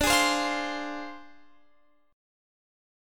Dm7b5 Chord (page 2)
Listen to Dm7b5 strummed